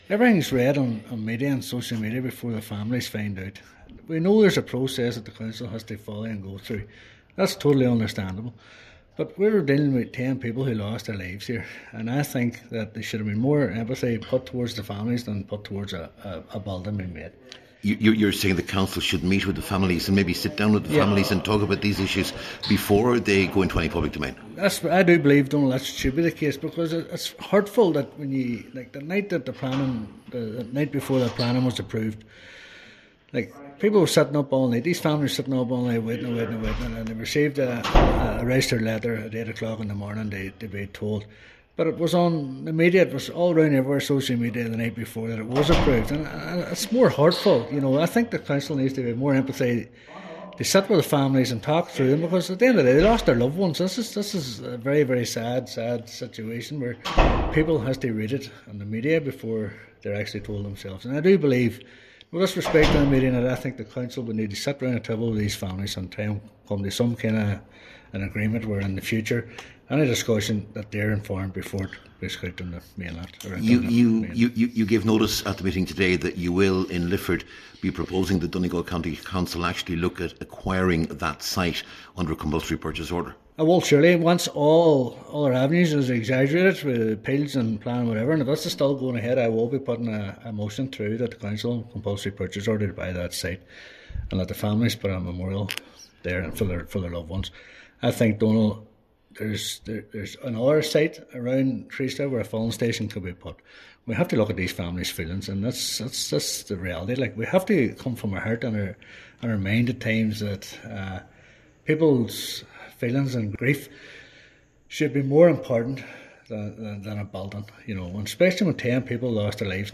Cllr Devine told the meeting he’ll be proposing that the council acquire the site through a CPO: